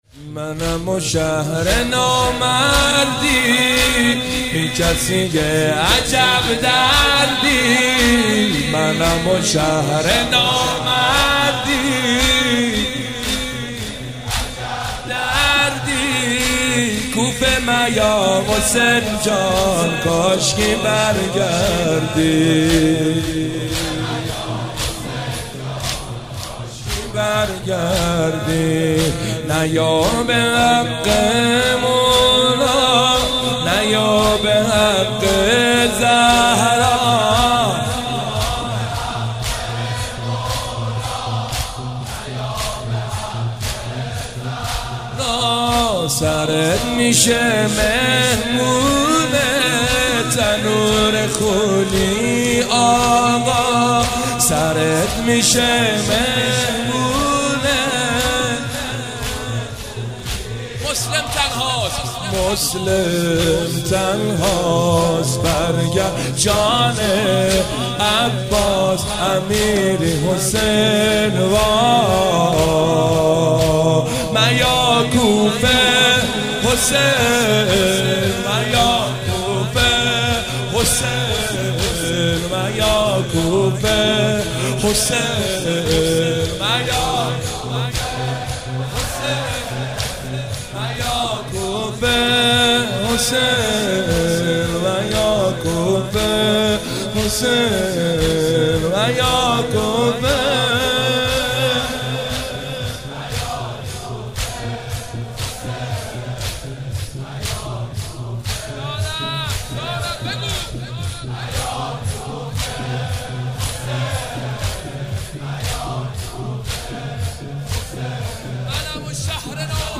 محرم 96 - زمینه - منم و شهر نامردی بی کسی عجب دردی
شب اول محرم - به نام نامی حضرت مسلم(ع)